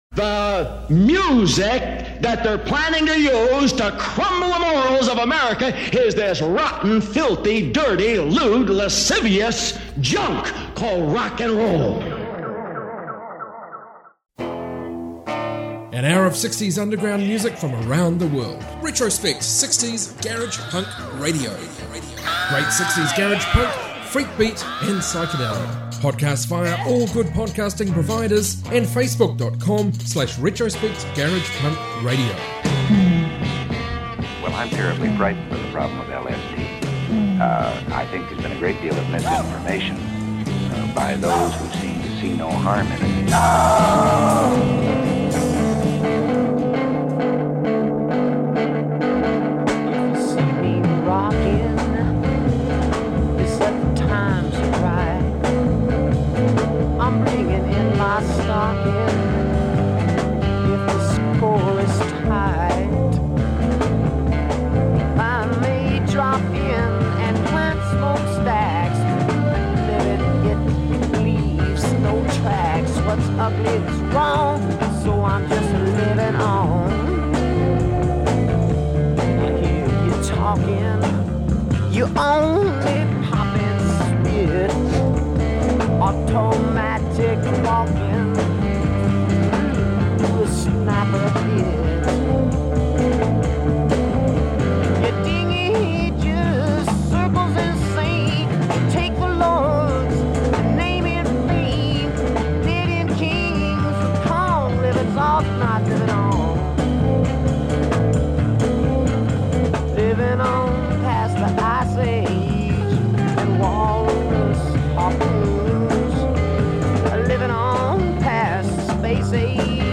60s global garage music